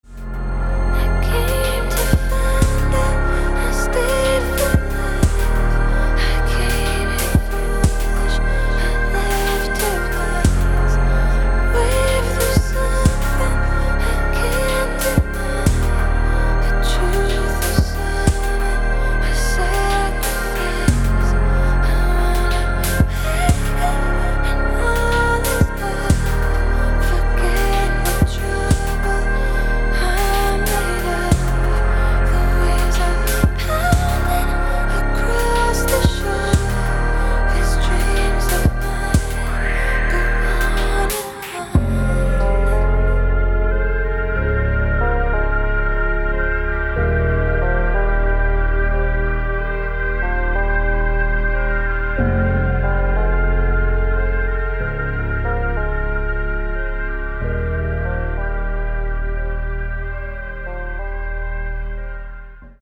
• Качество: 256, Stereo
женский вокал
атмосферные
электронная музыка
спокойные
Ambient
Chill
тихие